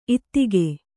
♪ ittige